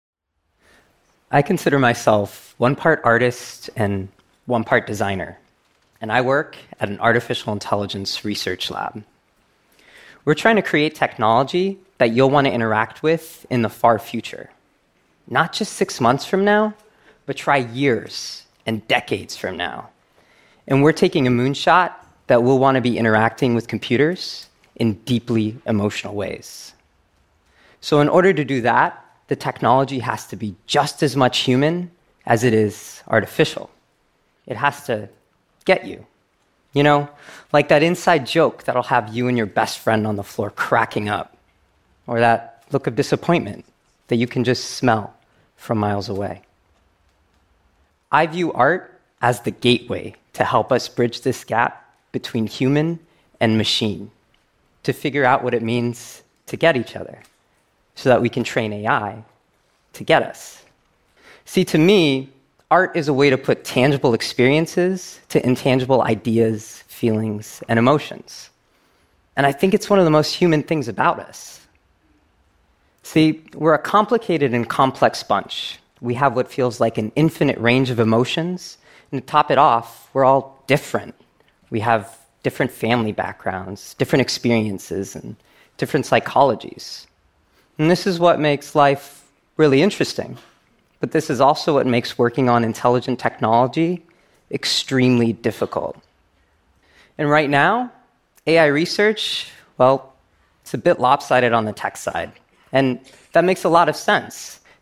TED演讲:怎样才能教会电脑理解我们的情感(1) 听力文件下载—在线英语听力室